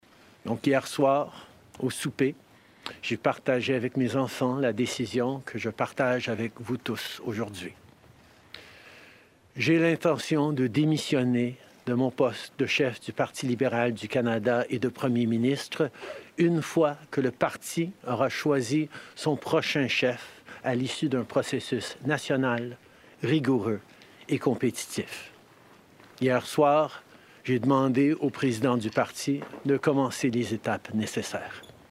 Le premier ministre du Canada s’est présenté devant sa résidence, sur le coup de 11 heures aujourd’hui.